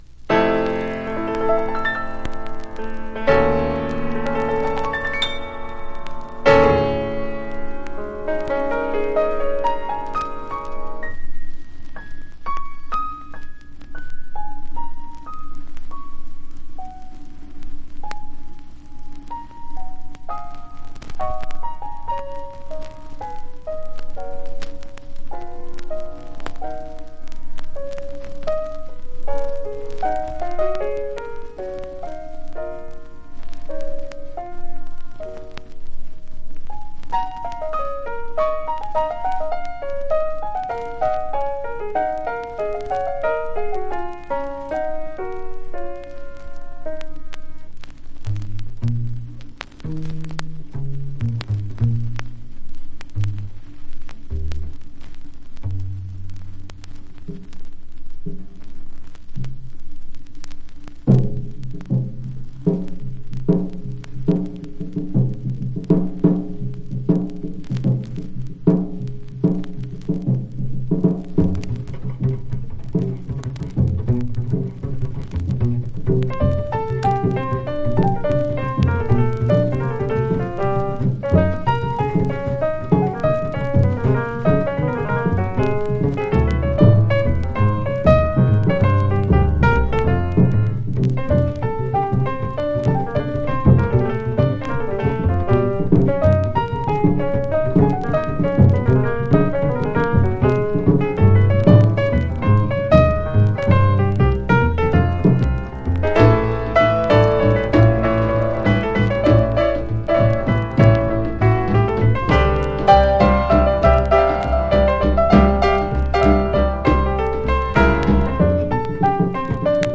（小傷によりチリ、プチ音ある曲あり）※曲名をクリックすると試…